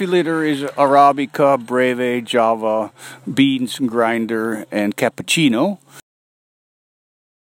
Current Location: WHITE MOUNTAIN, ALASKA
Temperature: 24F / OUTDOORS